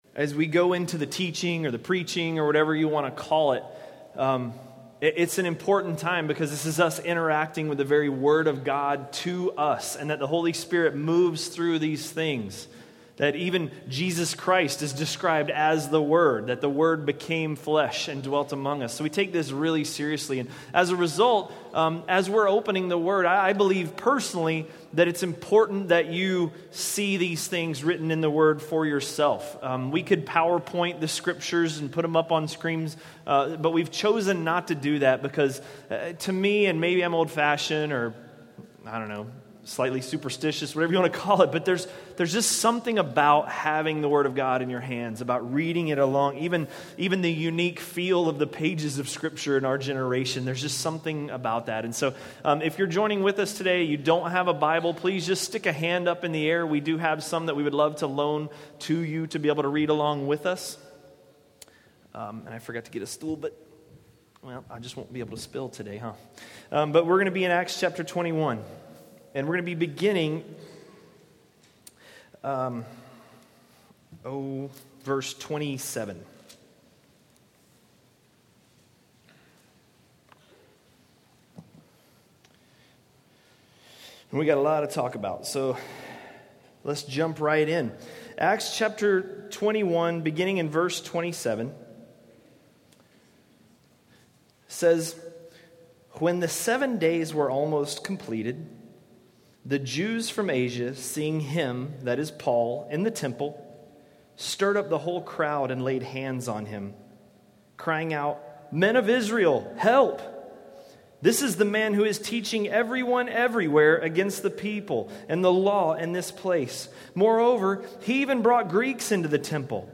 A message from the series "Acts." Acts 21:27–22:29